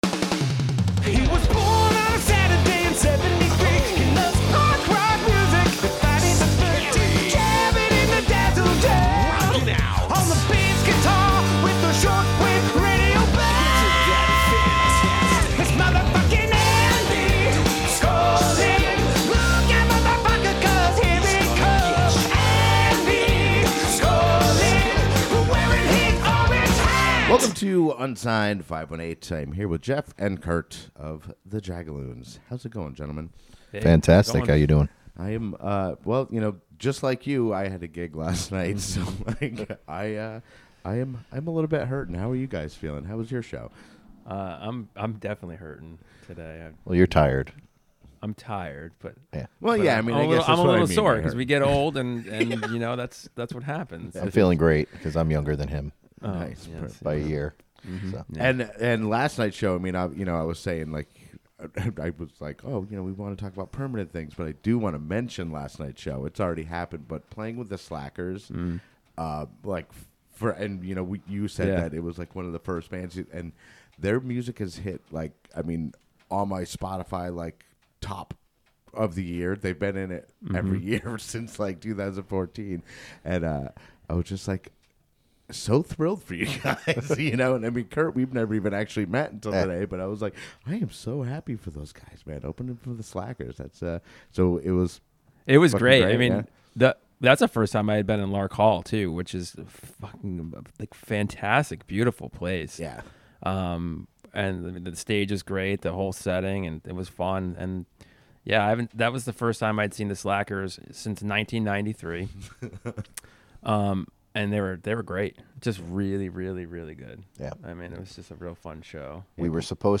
We talk about their upcoming album, as well as play a couple of tunes from the album, one of which has enlightened me to a simple, yet delicious breakfast food.